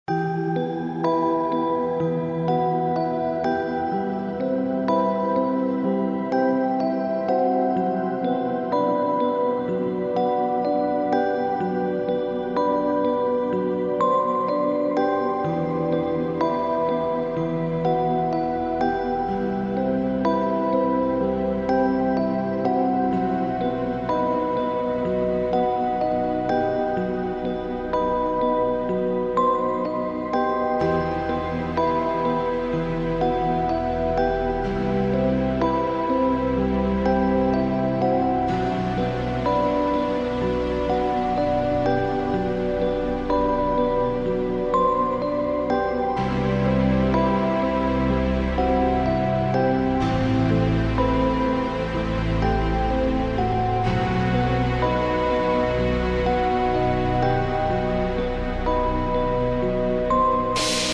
• Качество: 128, Stereo
атмосферные
без слов
колыбельные
погружающие